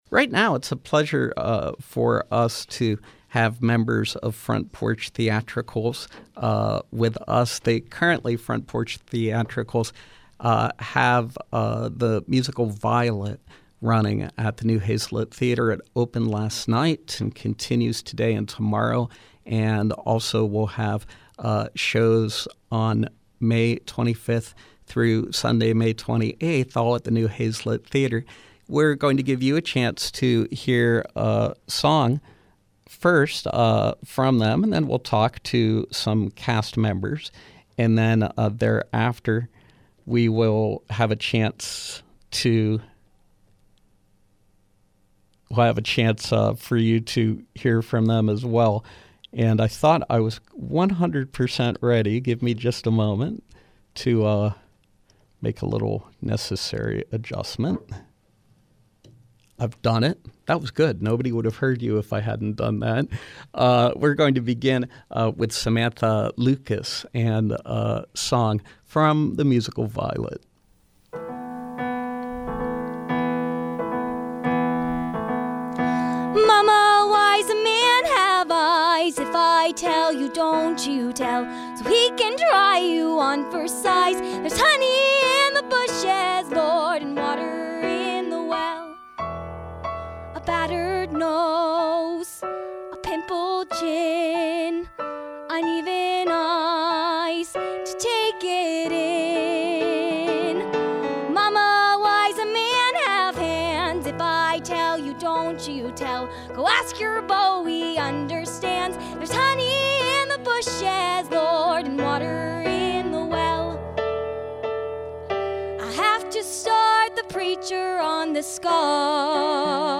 From 05/20/2017: Front Porch Theatricals with a live in-studio preview of the musical Violet, running 5/19 to 5/21 and 5/25 to 5/28 at the New Hazlett Theater.